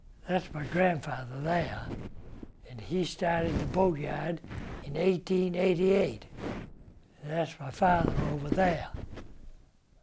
Here are some examples of lav-mic recordings where the person moved just a bit too much:
de-rustle.wav